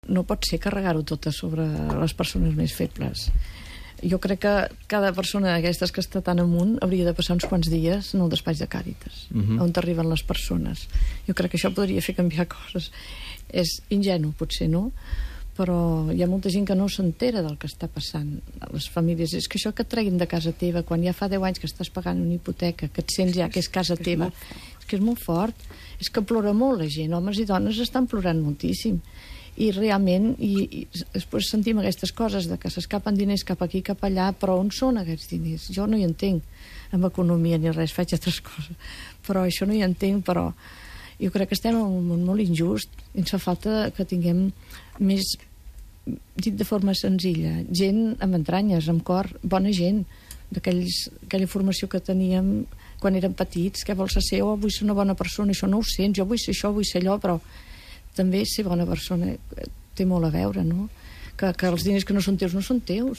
Entrevista
Explica què és El Xiprer i com s'està vivint la dura realitat dia a dia. Col.laboració d'altres tertulians que reflexionen sobre la crisi econòmica i les retallades socials.